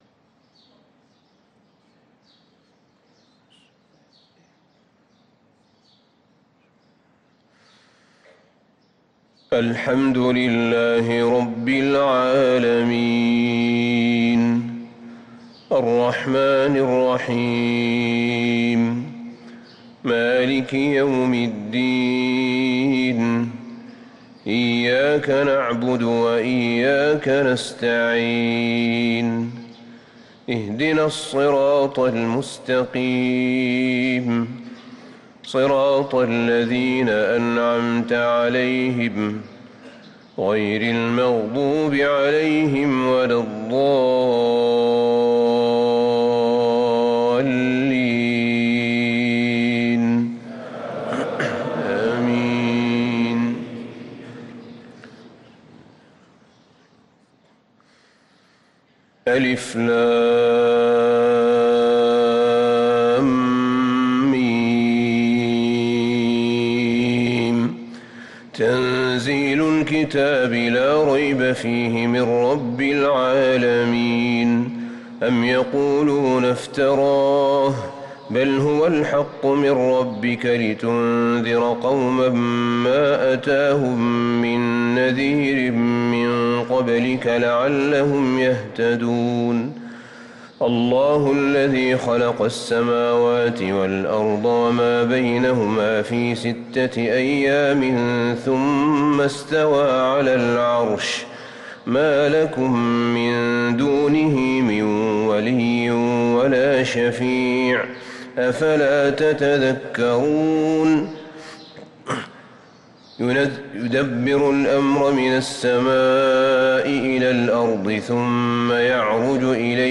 صلاة الفجر للقارئ أحمد بن طالب حميد 21 ربيع الأول 1445 هـ
تِلَاوَات الْحَرَمَيْن .